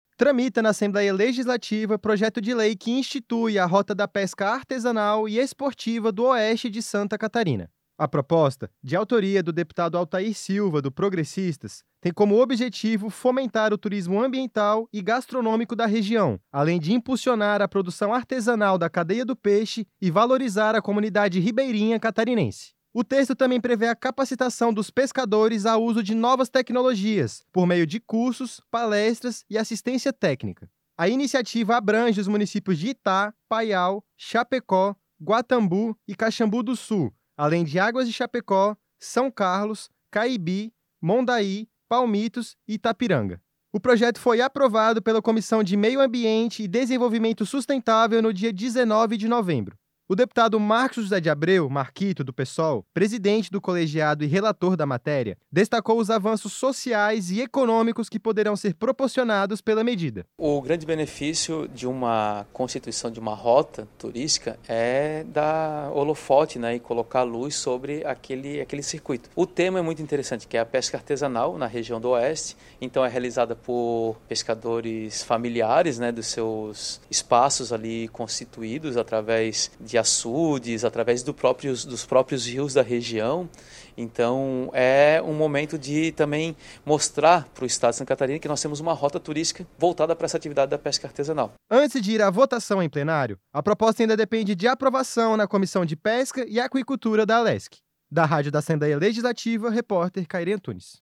Entrevista com:
- deputado Marcos José de Abreu - Marquito (Psol).